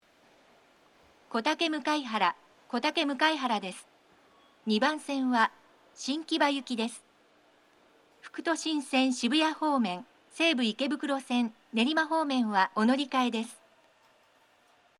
駅の放送STATION BROADCAST
到着放送
kotake_mukaihara-2_arrival.mp3